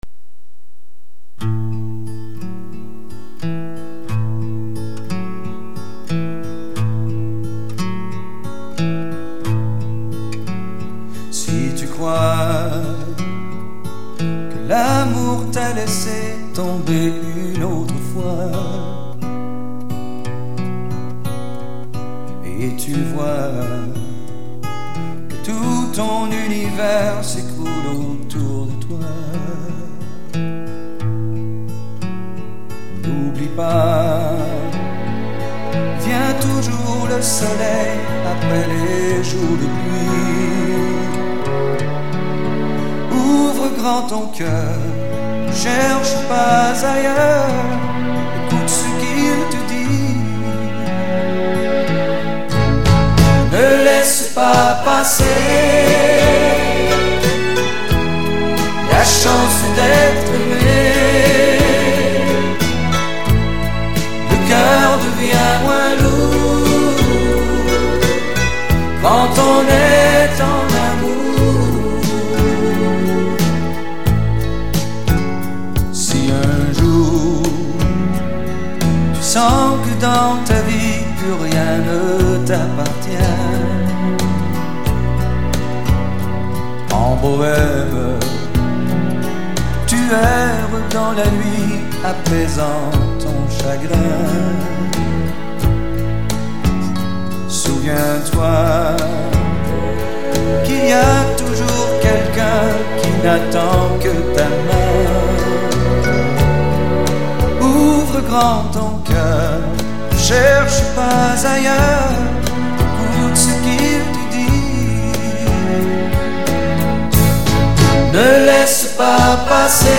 114e Cursillo Mixte  -  15 au 18 mars 2007